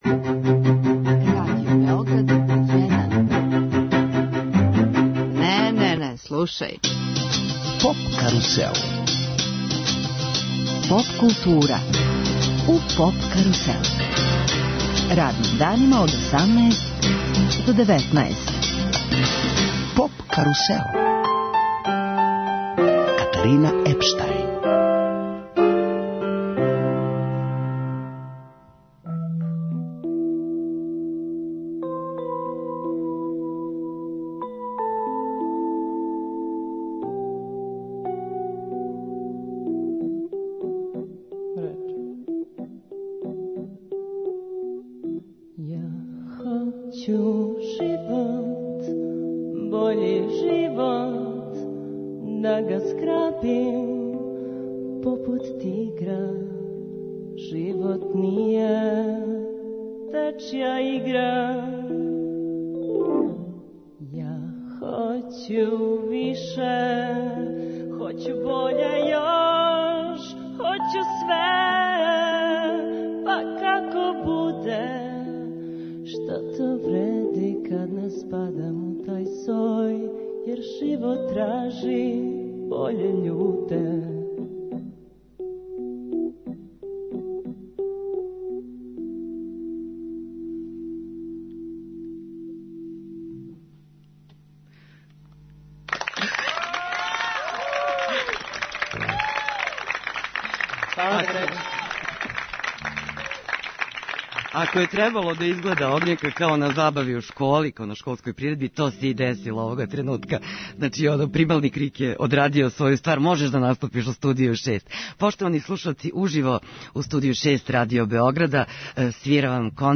Један од најзначајнијих алтернативних састава Аутопарк одржаће мини концерт у Студију 6 Радио Београда.